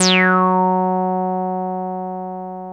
303 F#3 4.wav